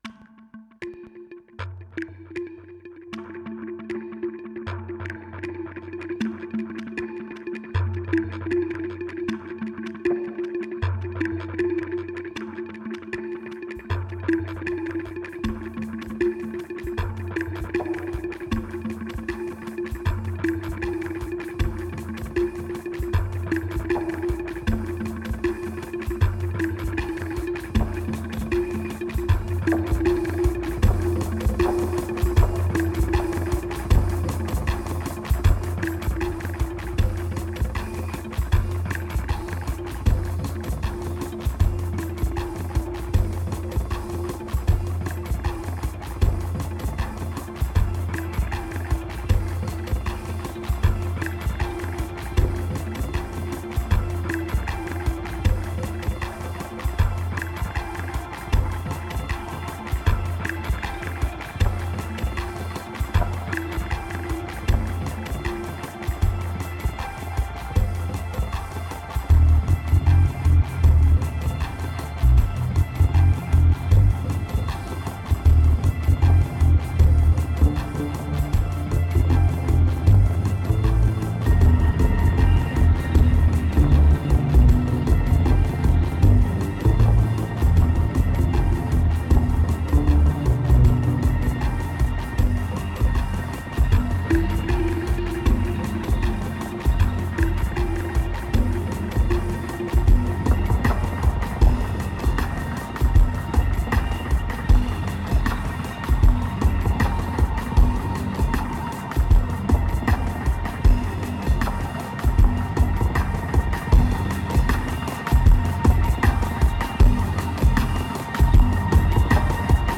2074📈 - 17%🤔 - 78BPM🔊 - 2011-04-10📅 - -97🌟